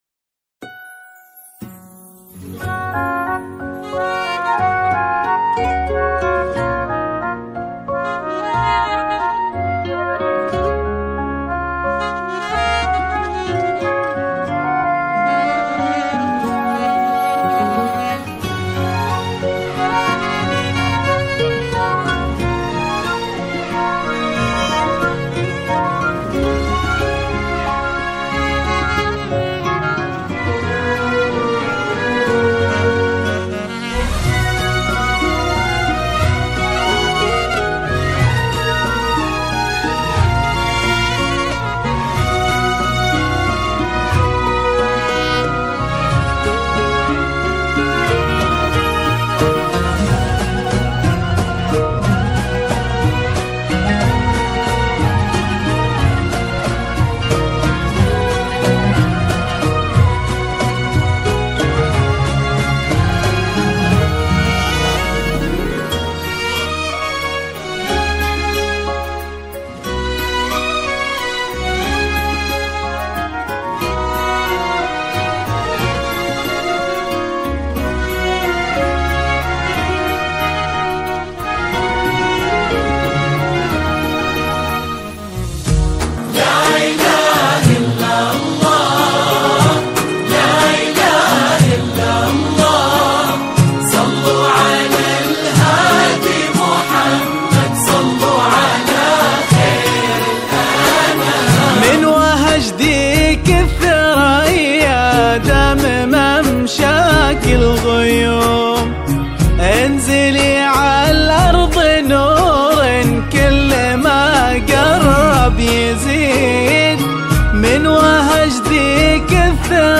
زفات 2025